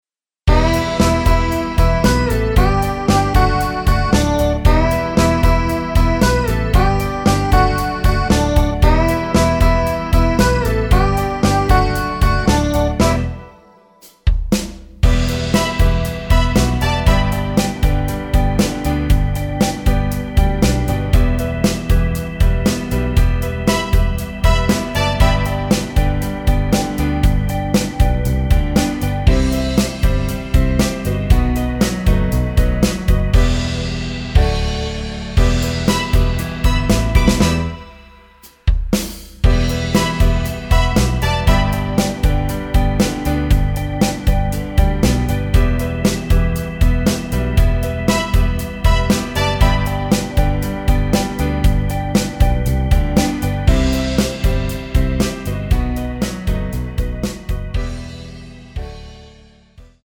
Em
앞부분30초, 뒷부분30초씩 편집해서 올려 드리고 있습니다.
중간에 음이 끈어지고 다시 나오는 이유는